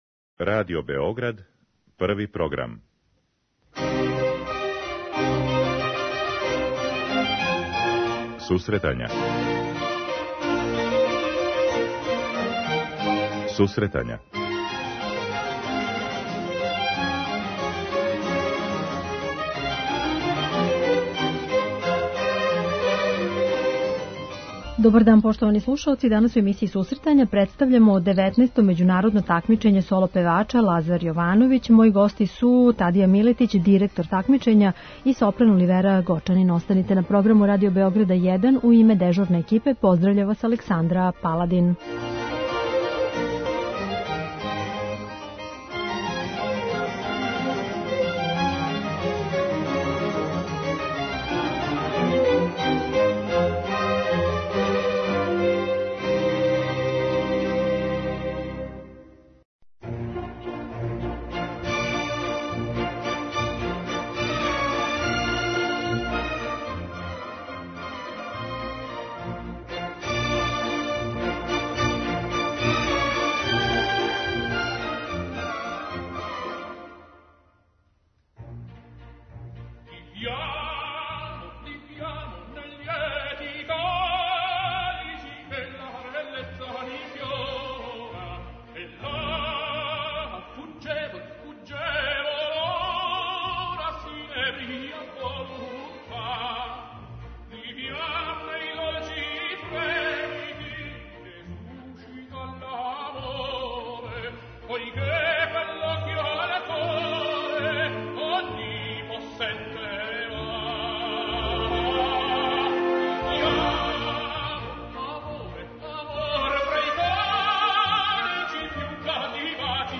Међународно такмичење "Лазар Јовановић", које и ове године окупља такмичаре - соло певаче од 16 до 33 године. преузми : 10.13 MB Сусретања Autor: Музичка редакција Емисија за оне који воле уметничку музику.